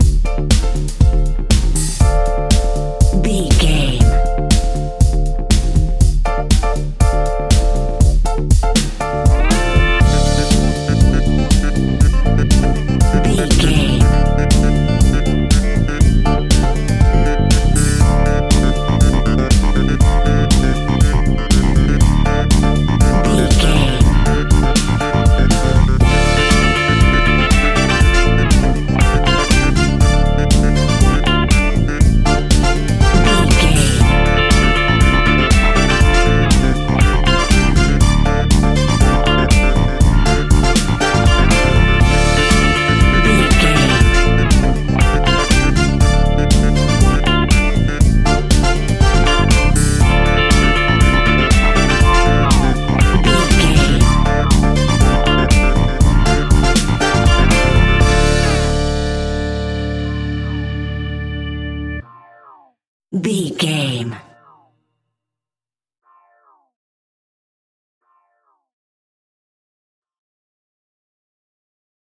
Aeolian/Minor
D
funky
groovy
uplifting
driving
energetic
strings
brass
bass guitar
electric guitar
electric organ
synthesiser
drums
funky house
disco house
electronic funk
upbeat
synth leads
Synth Pads
synth bass
drum machines